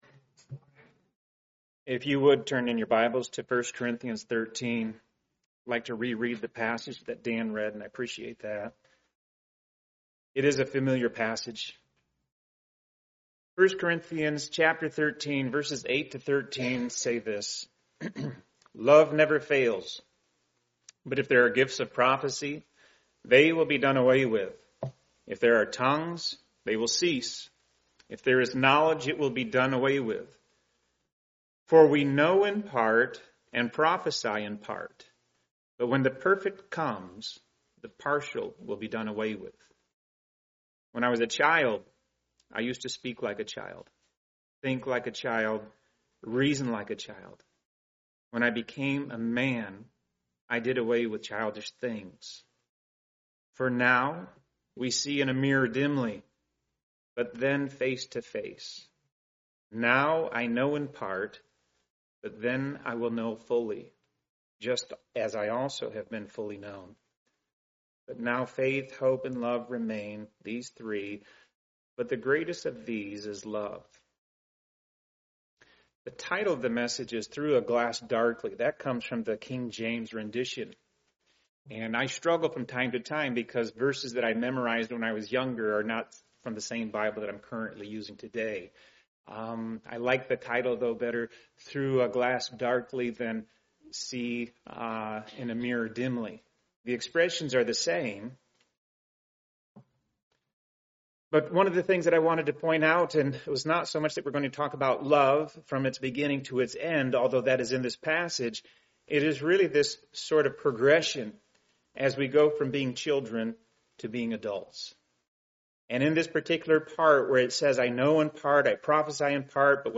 Part of the Topical series, preached at a Morning Service service.